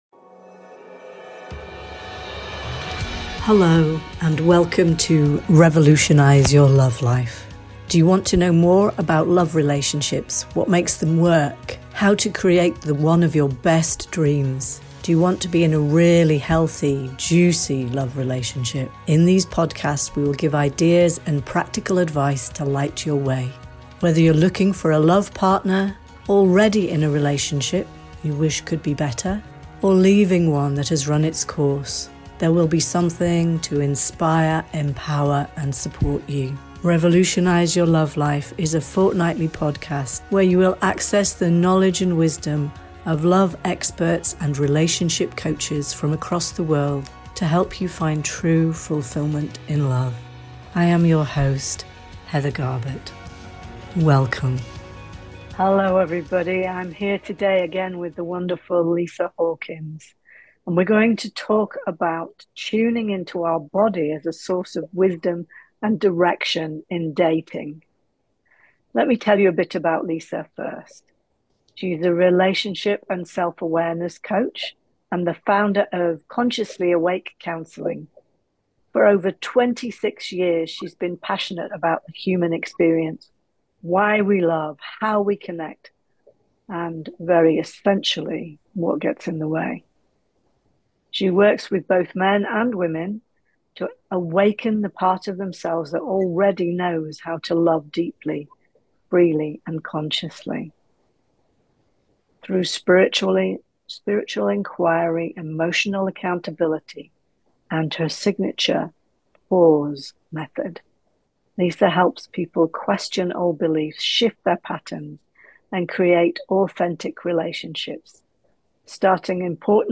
They also speak about releasing long held emotion from the body and choosing curiosity over criticism. This is a kind conversation for anyone who wants to date with self respect, steadiness and joy.